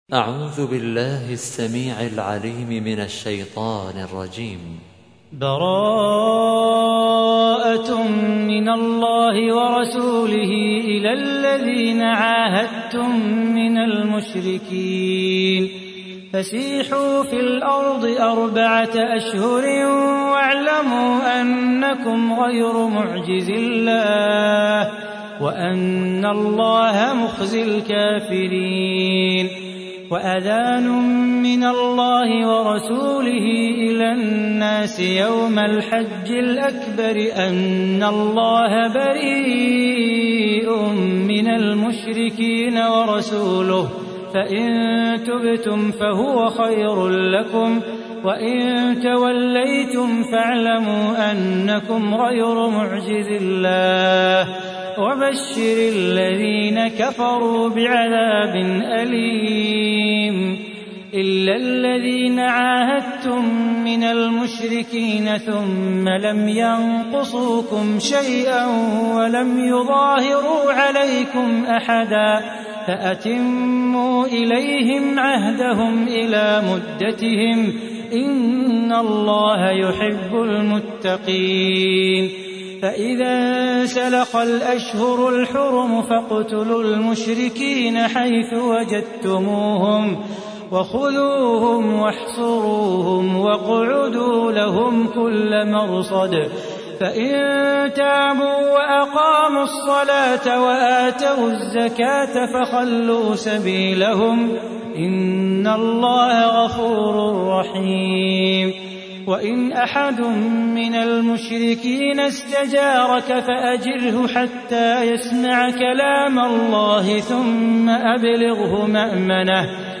تحميل : 9. سورة التوبة / القارئ صلاح بو خاطر / القرآن الكريم / موقع يا حسين